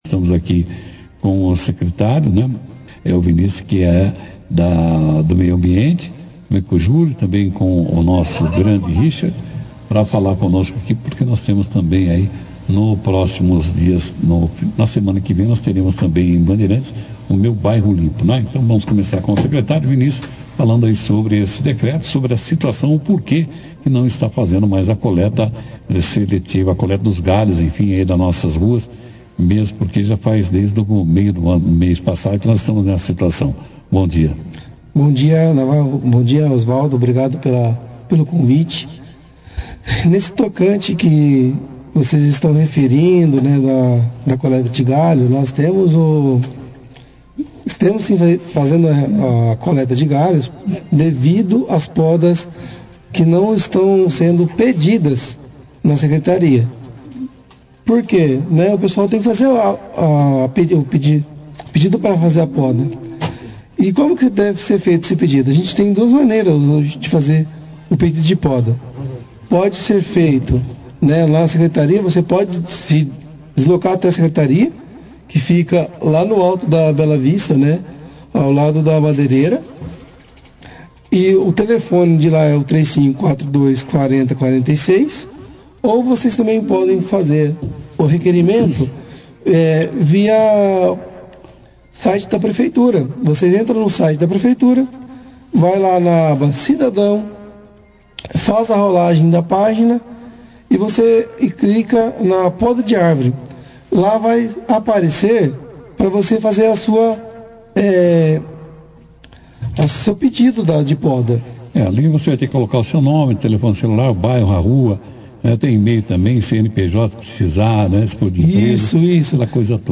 participaram de uma entrevista na 2ª edição do jornal “Operação Cidade”